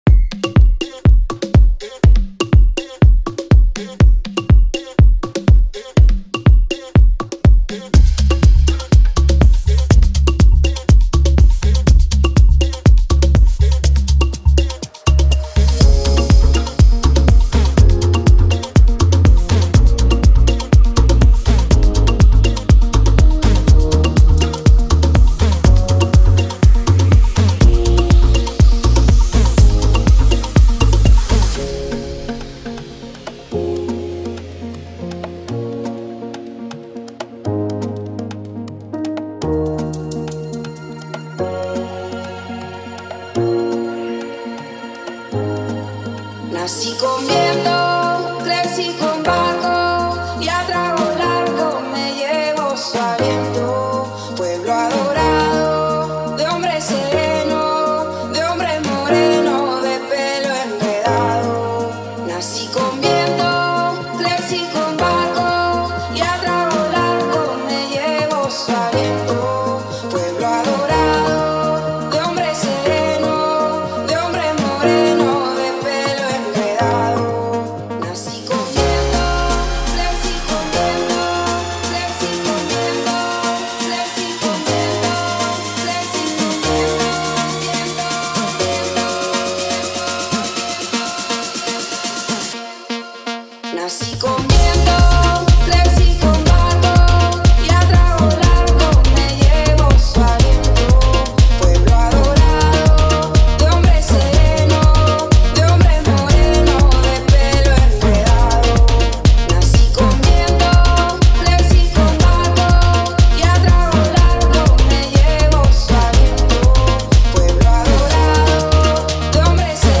Fusion of Afro and Latin House